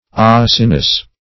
Acinus \Ac"i*nus\ ([a^]s"[i^]*n[u^]s), n.; pl. Acini. [L.,